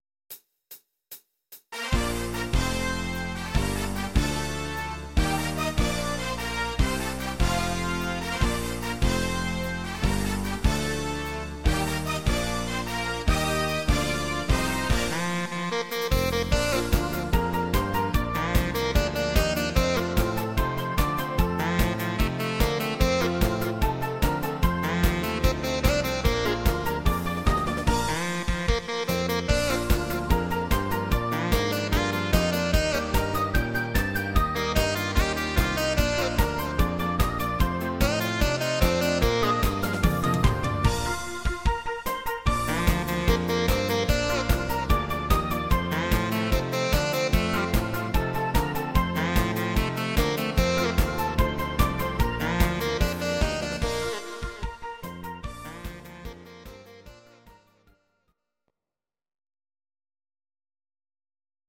These are MP3 versions of our MIDI file catalogue.
Please note: no vocals and no karaoke included.
instr. tenor sax